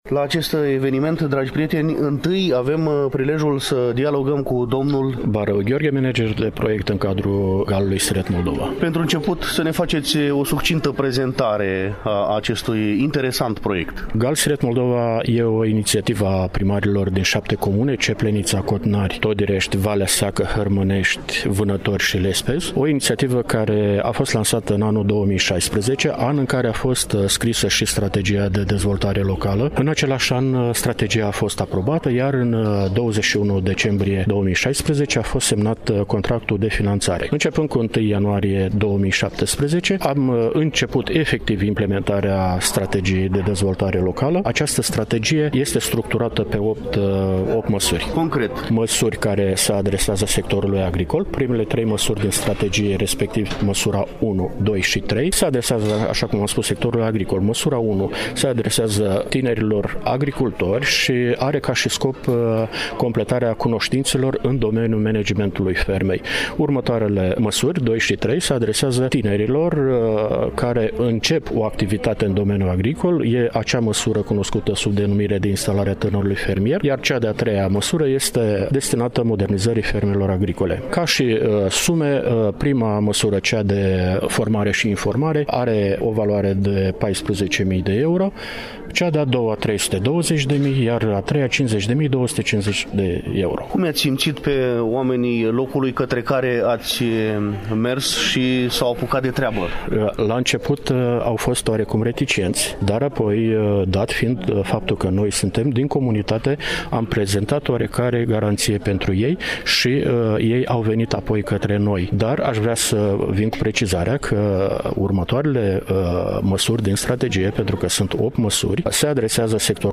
Evenimentul a avut loc în incinta Hanului Andri Popa din Comuna Valea Seacă, Iași, în ziua de vineri, 6 septembrie, începând cu ora 10.